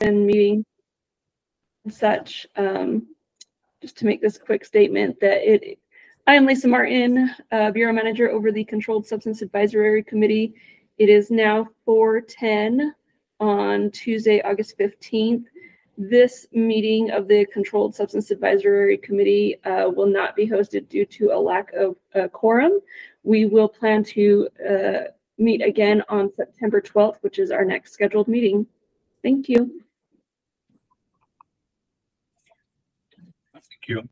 Controlled Substance Advisory Committee meeting
Electronic participation is planned for this meeting.